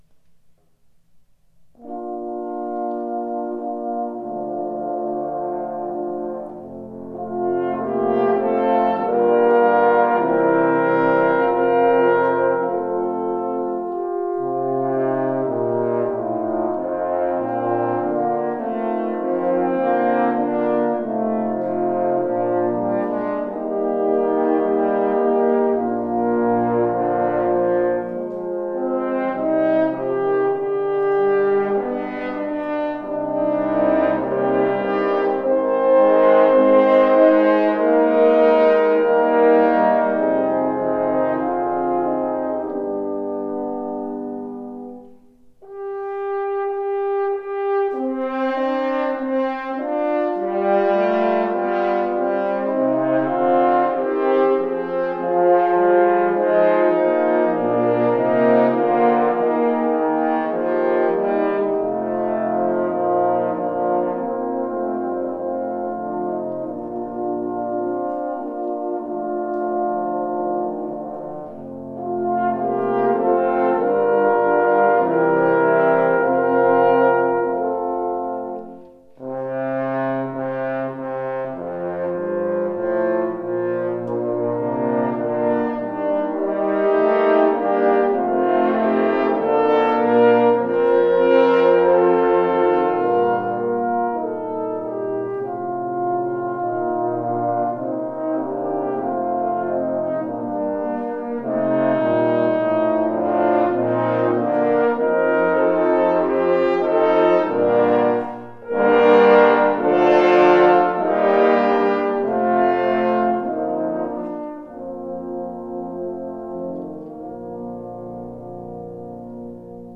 Arrangement / Hornensemble
Bearbeitung für Hornsextett
Besetzung: 6 Hörner
Arrangement for horn sextet
Instrumentation: 6 horns